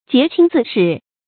潔清自矢 注音： ㄐㄧㄝ ˊ ㄑㄧㄥ ㄗㄧˋ ㄕㄧˇ 讀音讀法： 意思解釋： 謂保持自身清廉正直。